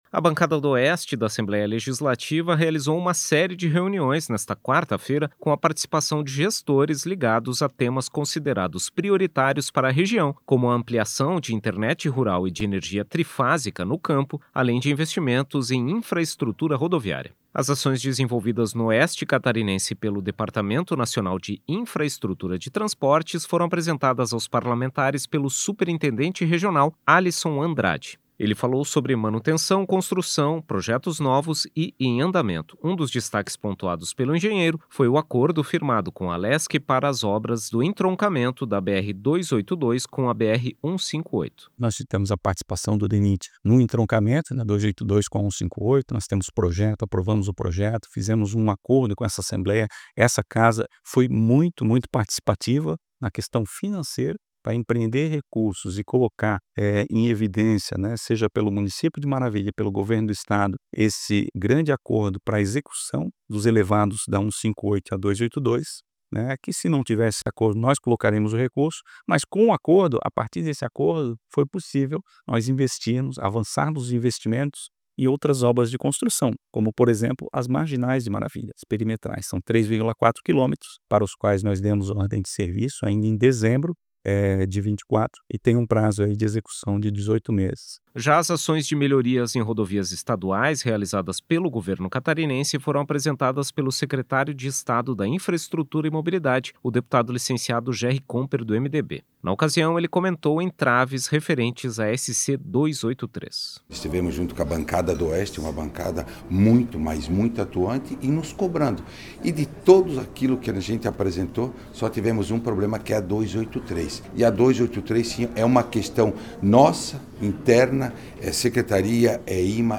Entrevistas com: